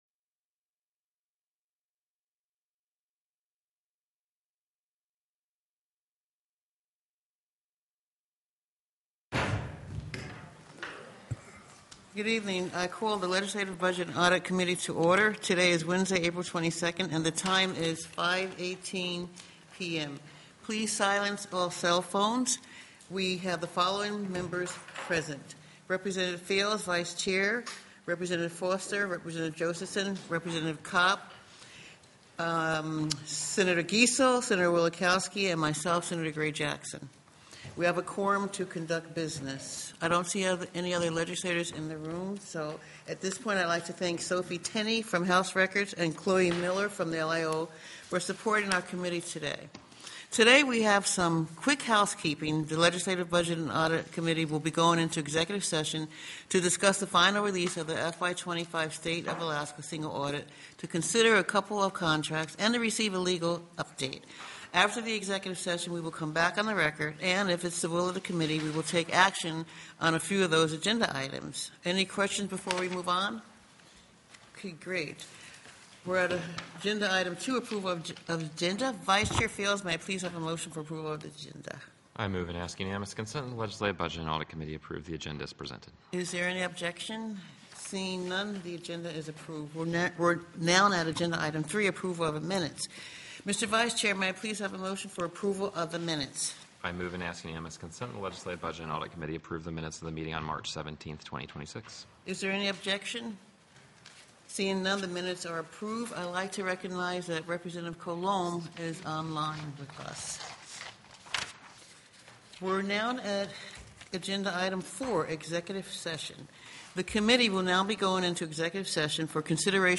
The audio recordings are captured by our records offices as the official record of the meeting and will have more accurate timestamps.
+ I. Approval of the Agenda TELECONFERENCED